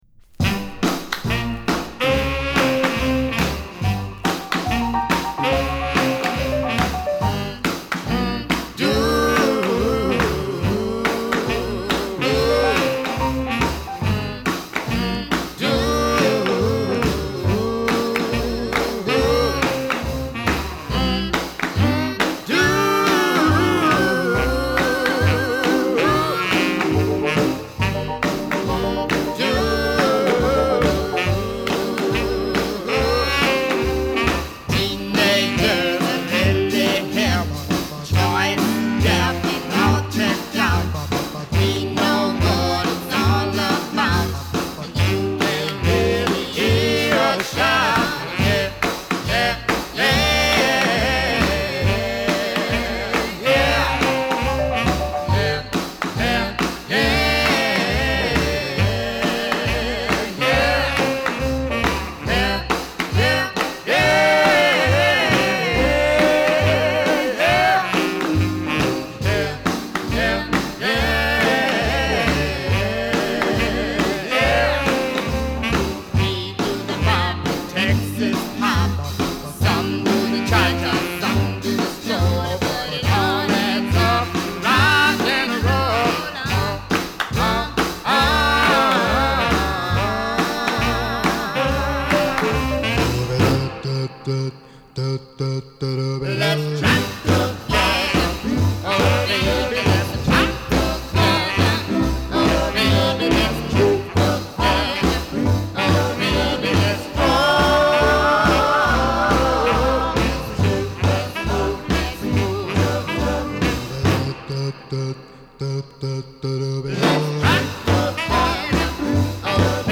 ムーディなマイナー調のR&Bグループ・ロッカー。
音のトーンに翳りはあるものの、ハンドクラップも入って思いの外ダンサブルである。